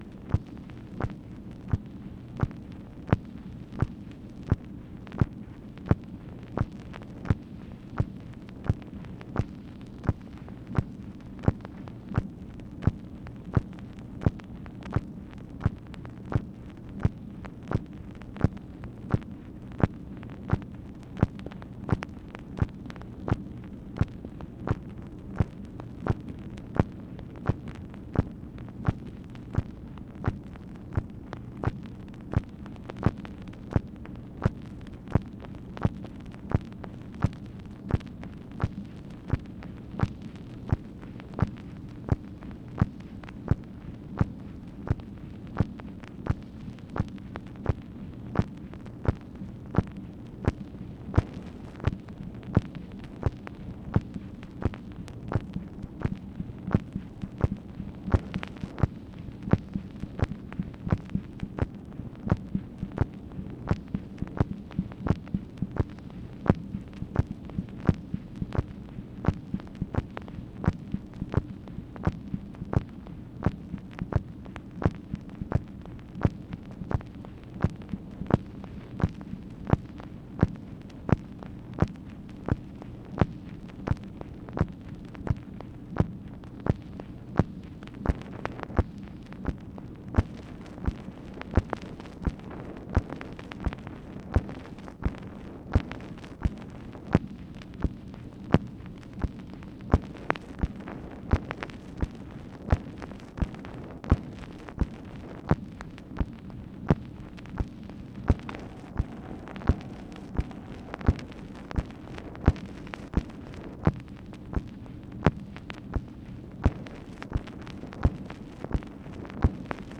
MACHINE NOISE, July 25, 1966
Secret White House Tapes | Lyndon B. Johnson Presidency